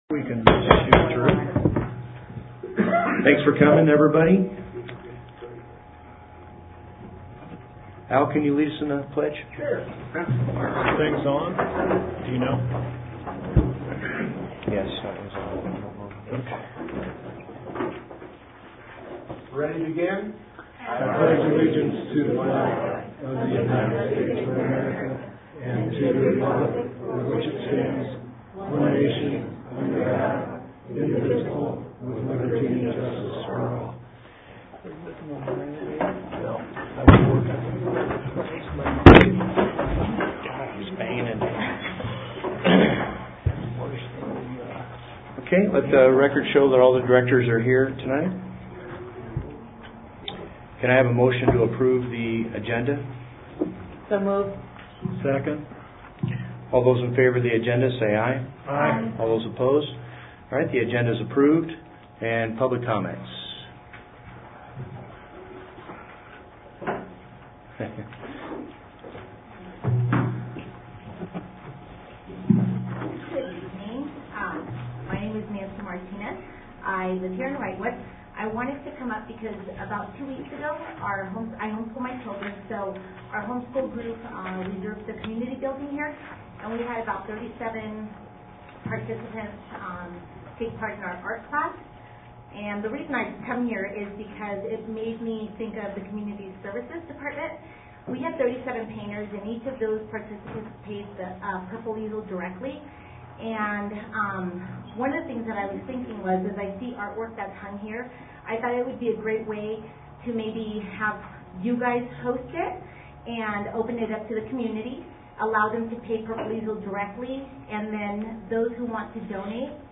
Audio from meeting